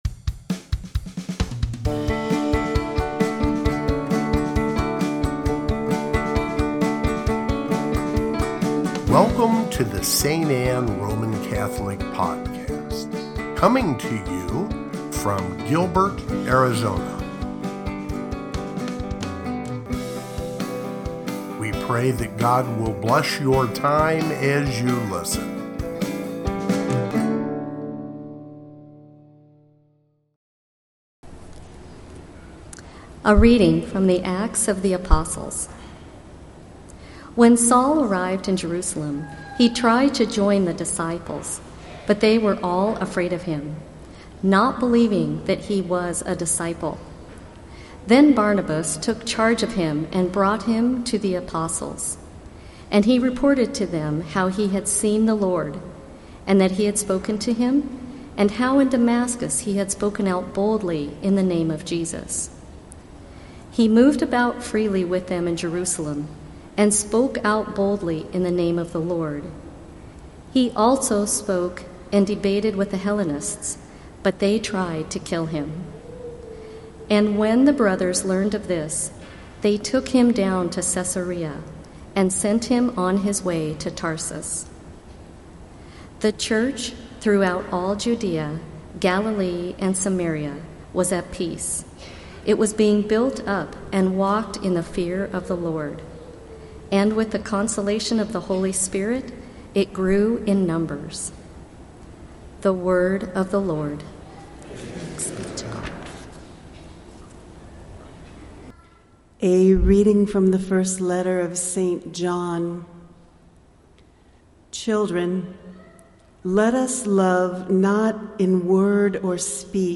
Sixth Sunday of Easter (Homily)